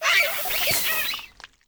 Grito de Grafaiai.ogg
) Categoría:Gritos de Pokémon de la novena generación Categoría:Grafaiai No puedes sobrescribir este archivo.
Grito_de_Grafaiai.ogg